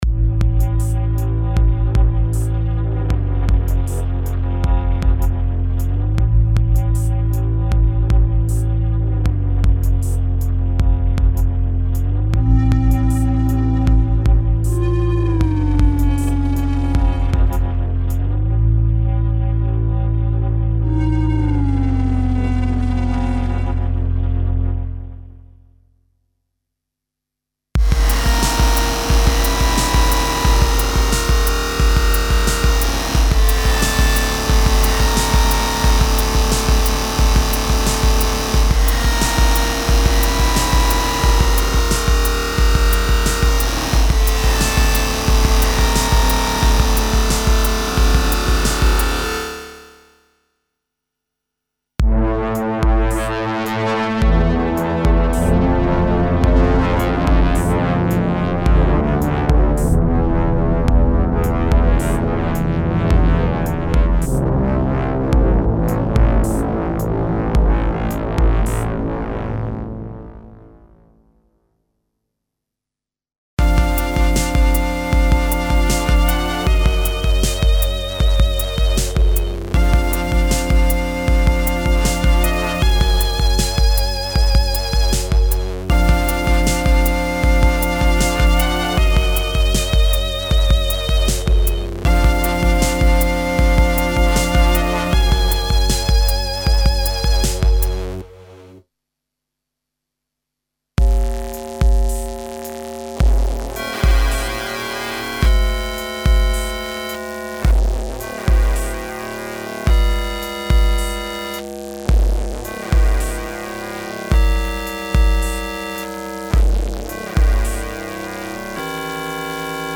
Raw digital and gritty synth pads with extreme modulations (e.g. filter, pitch, shaper, distortion, etc.) and lo-fi feeling for hard electronica fans.
Info: All original K:Works sound programs use internal Kurzweil K2600 ROM samples exclusively, there are no external samples used.
Note: This soundware collection is designed for Kurzweil K2600/K2600R synthesizers without optional ROM boards installed.